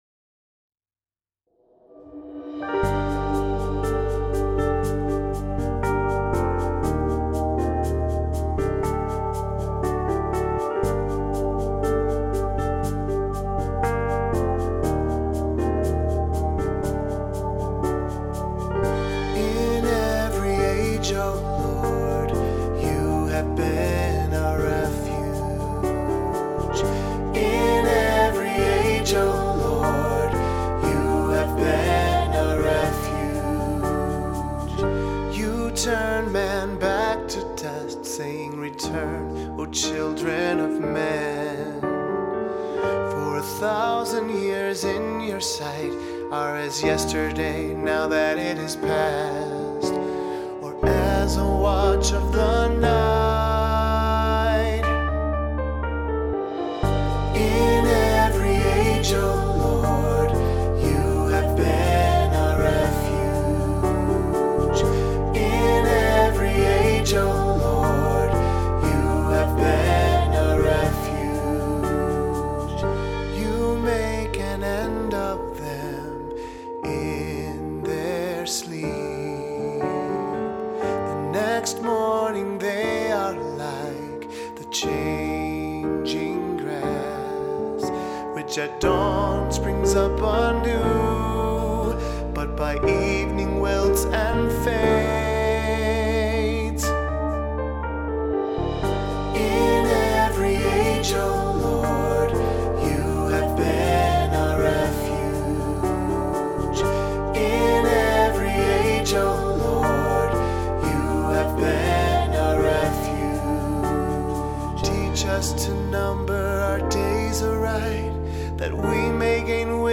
Voicing: Two-part choir; Cantor; Assembly